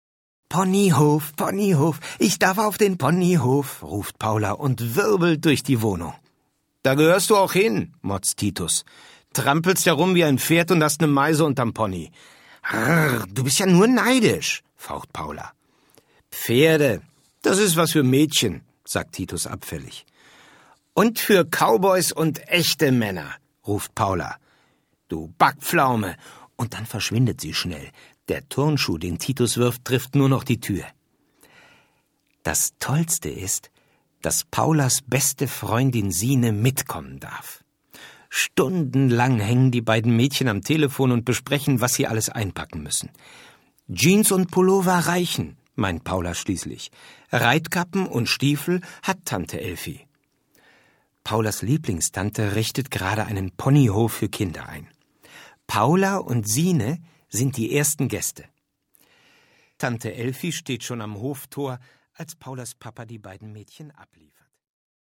Passende Musik zu den einzelnen Geschichten rundet diesen Hörspaß für kleine Ponyfreunde ab!